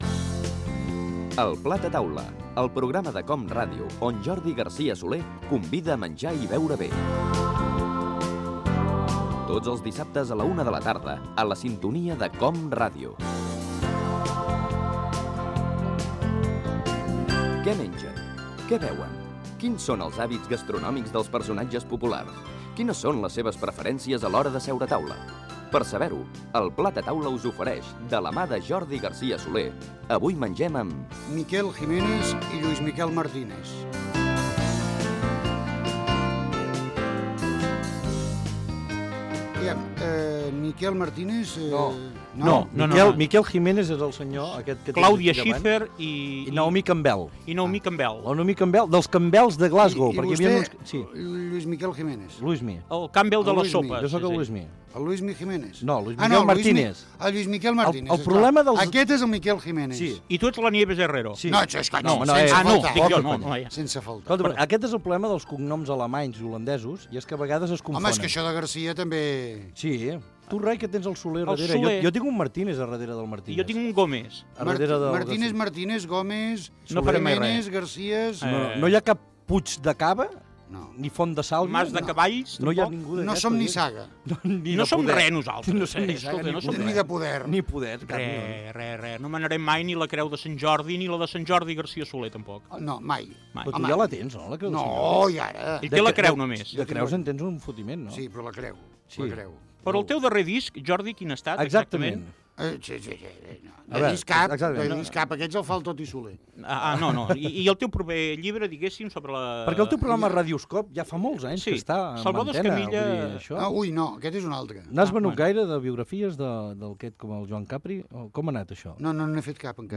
Careta del programa, presentació i fragment d'una entrevista amb els periodistes/humoristes
Entreteniment
FM